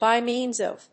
アクセントby mèans of…